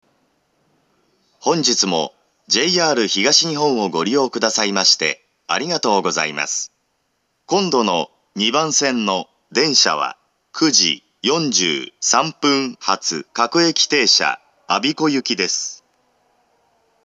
２番線到着予告放送